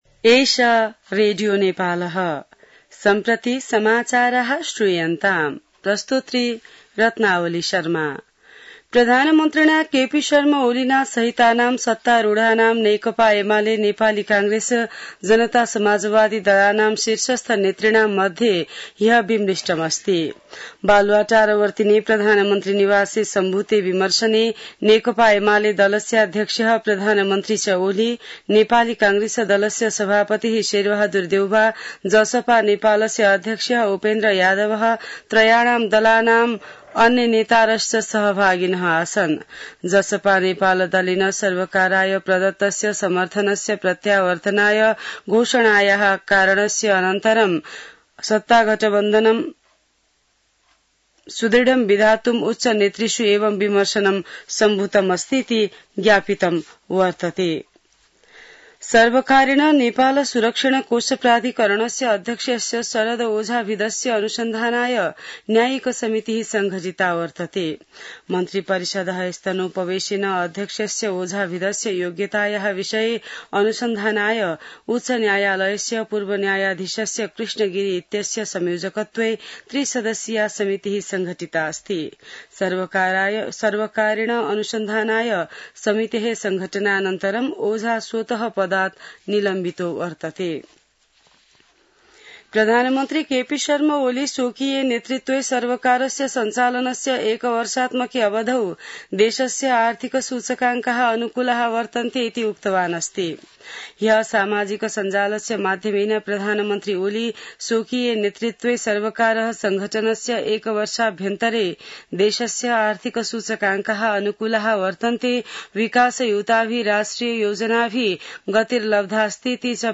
संस्कृत समाचार : ३१ असार , २०८२